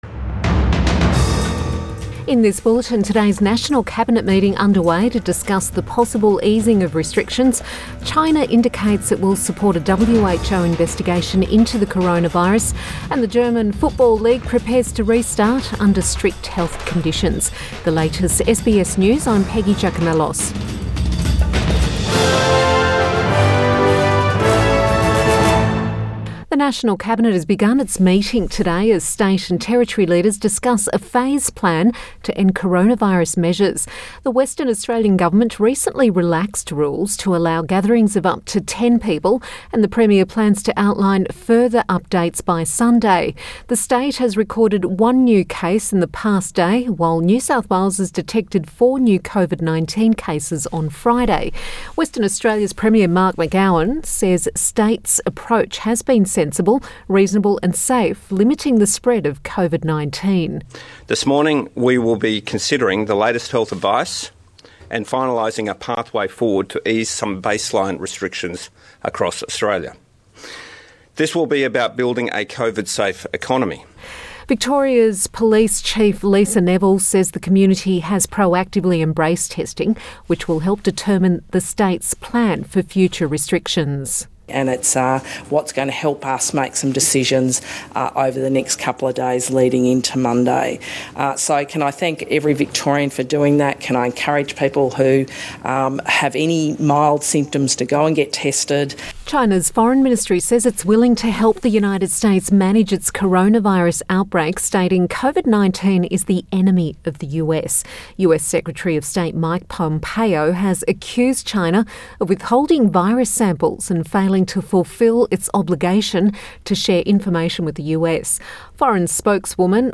Midday bulletin May 2020